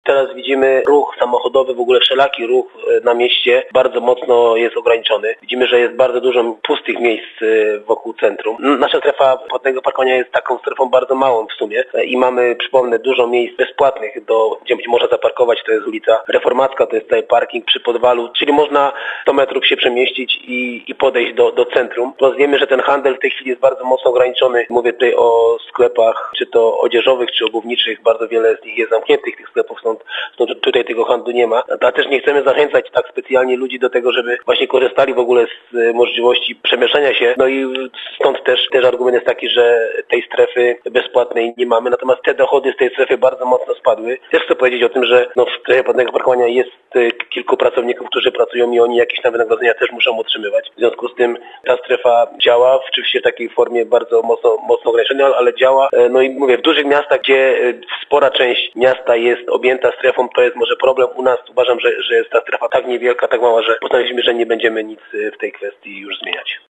Takie rozwiązania mają sens tylko w dużych miastach – mówił w rozmowie z nami burmistrz Paweł Okrasa.